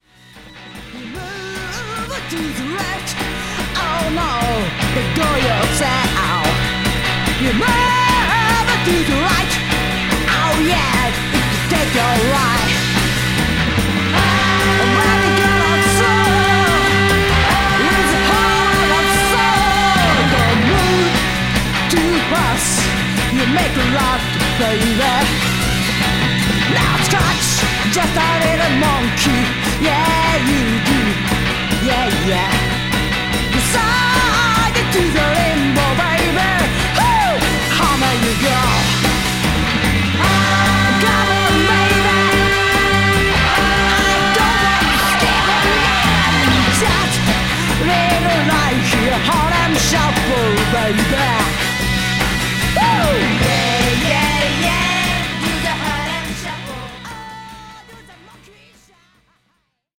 60's Mod/R&B、ガレージ・ソウル、さらにはニューオーリンズ・テイストまで幅広い内容